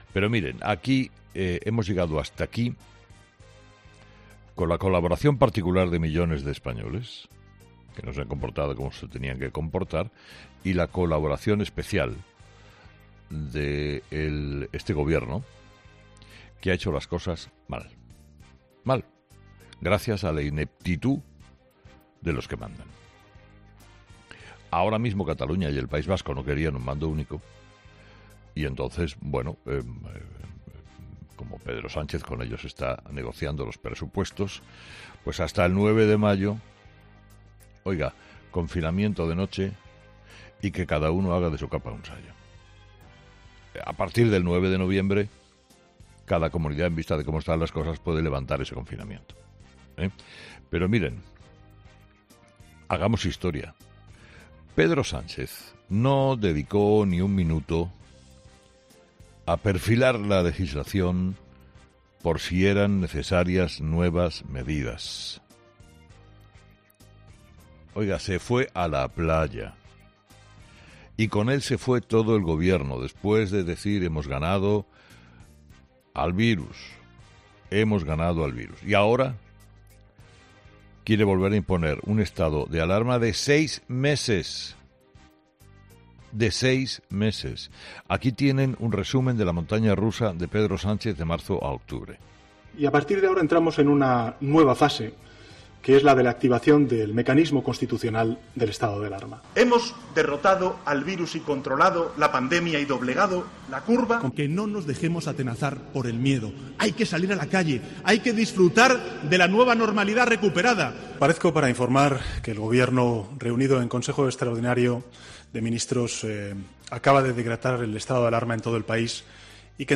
El director de 'Herrera en COPE', Carlos Herrera, analiza las claves del nuevo estado de alarma anunciado por Pedro Sánchez
Herrera explica las claves del nuevo estado de alarma